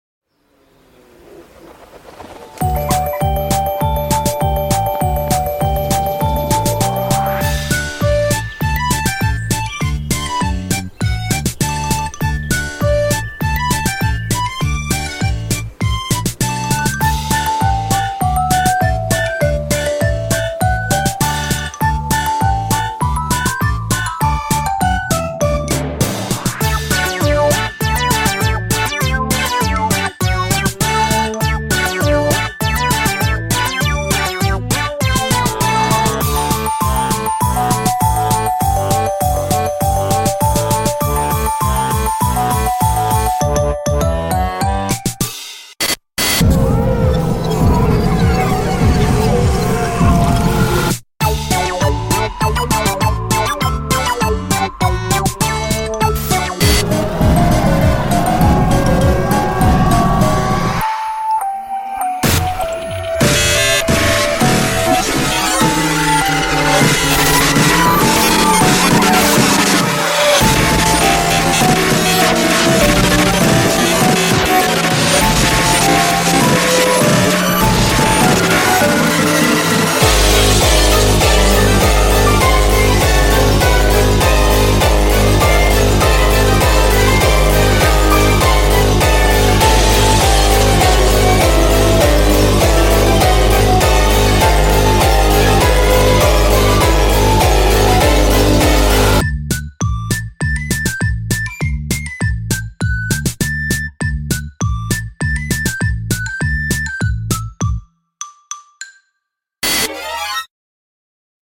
BPM50-400
Audio QualityPerfect (High Quality)
This cute song has some pretty happy vibes!